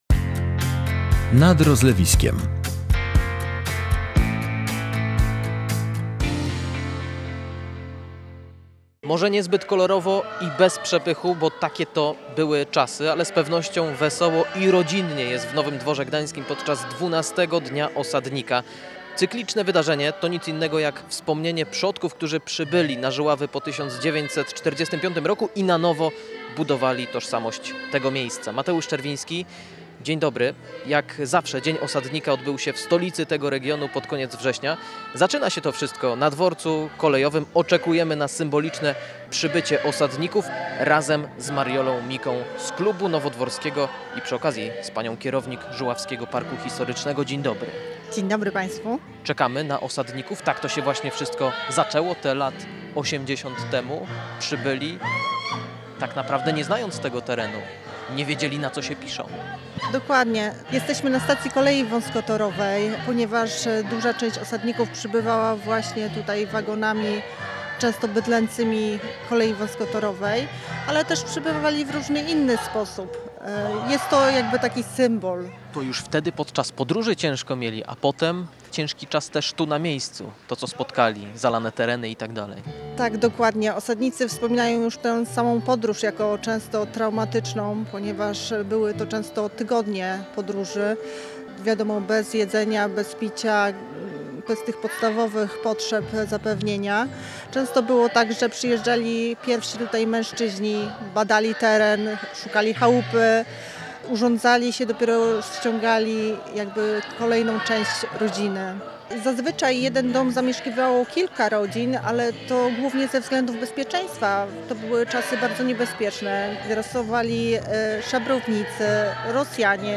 Niezbyt kolorowo i bez przepychu, bo takie to były czasy, ale z pewnością wesoło i rodzinnie było w Nowym Dworze Gdańskim podczas XII Dnia Osadnika. Cykliczne wydarzenie to nic innego jak wspomnienie przodków, którzy przybyli na Żuławy po 1945 roku i na nowo budowali tożsamość tego miejsca.